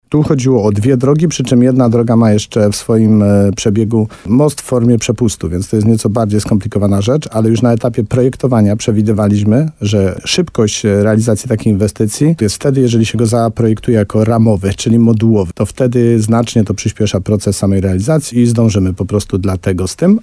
Wójt Tadeusz Królczyk podkreśla, że oba są istotne dla mieszkańców, bo łączą mniejsze osiedla z głównymi drogami.